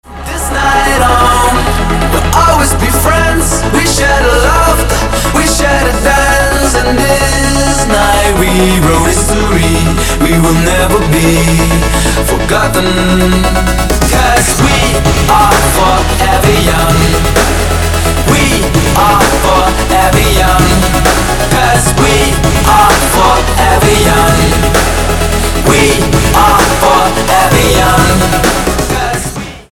• Качество: 256, Stereo
мужской вокал
electro house